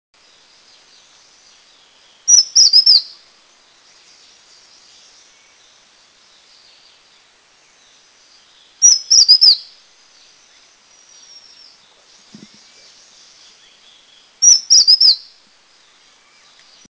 Tesia castaneocoronata
call
Chestnut-headed Tesia
Chestnut-headedTesiaCall.mp3